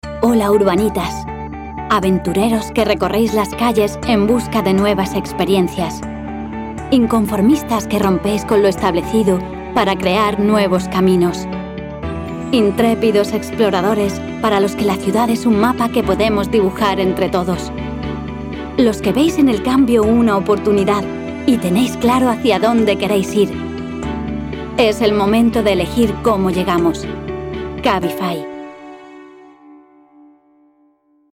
Female
Bright, Cheeky, Children, Cool, Corporate, Friendly, Natural, Warm, Witty, Versatile, Young
Spanish (Castilian, Andalusian)
Microphone: Neumann TL 103